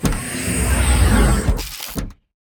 dooropen4.ogg